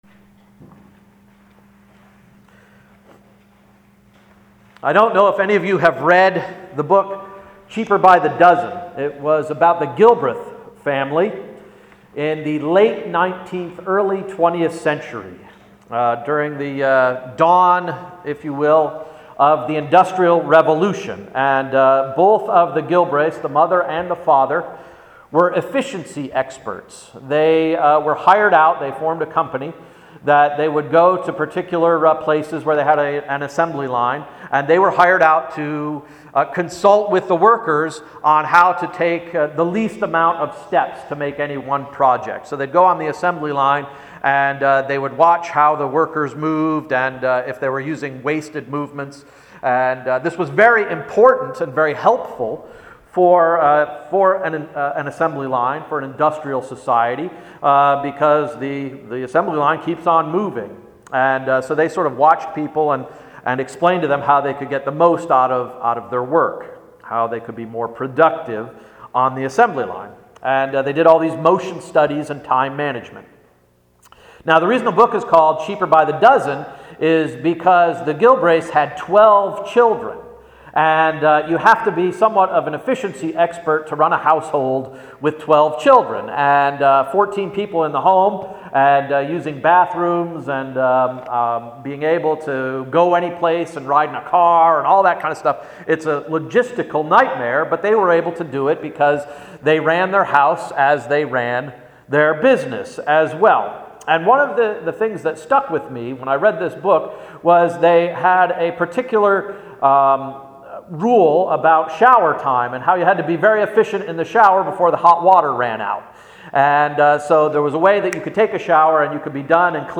Sermon of January 12, 2014–“Welcome Water”